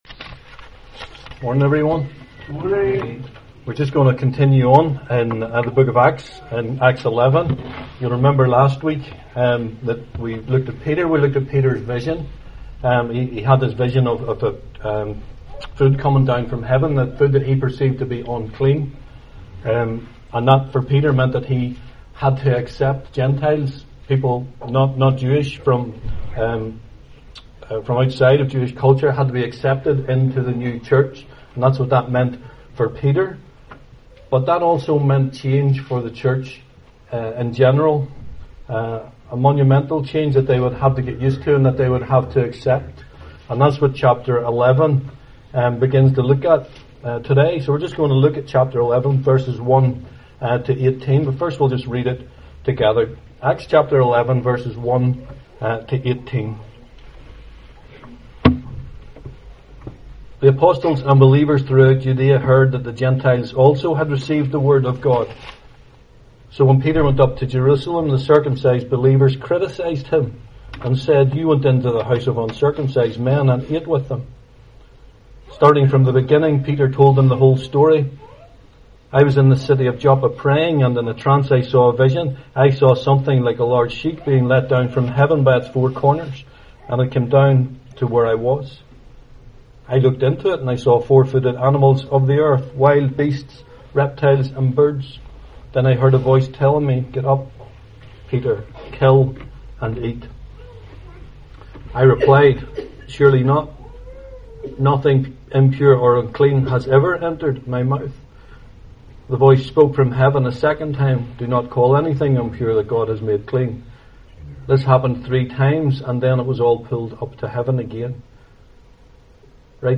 Service Type: 11am